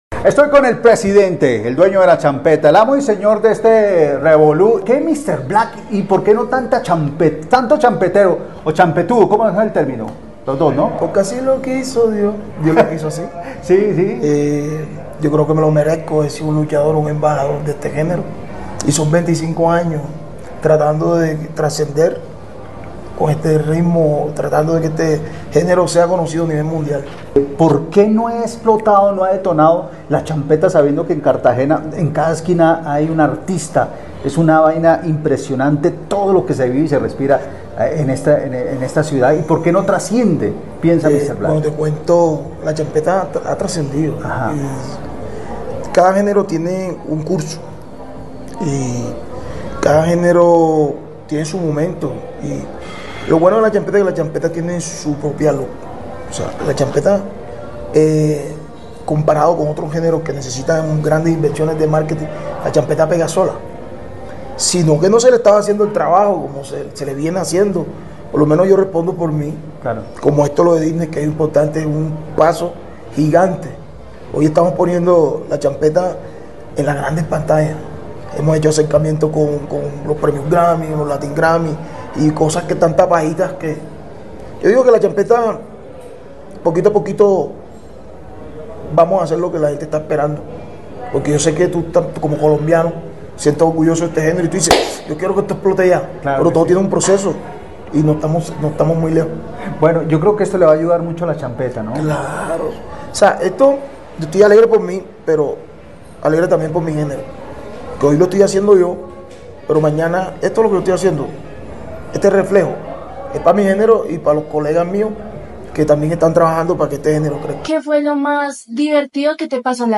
ENTREVISTA-MR-BLACK-PARA-DISNEY-CHANNEL-FT-STARMOST-LQSC-2025.mp3